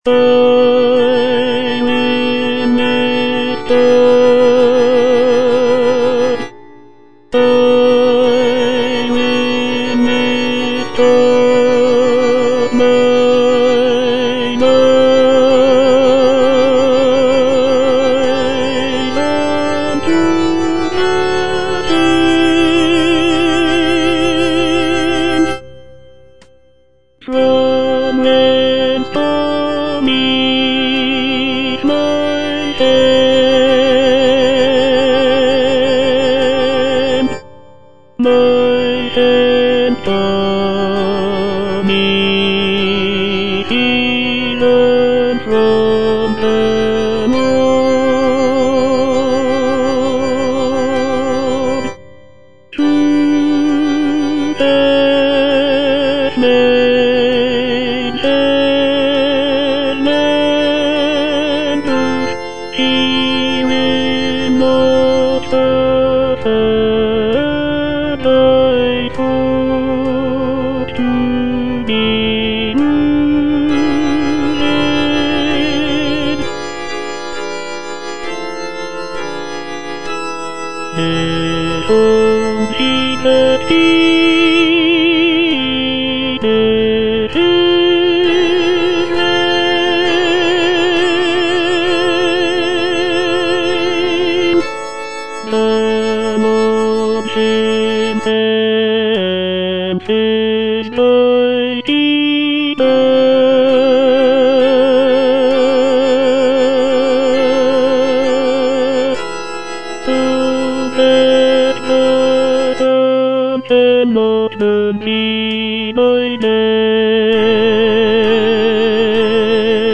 Tenor I (Voice with metronome)
is a choral work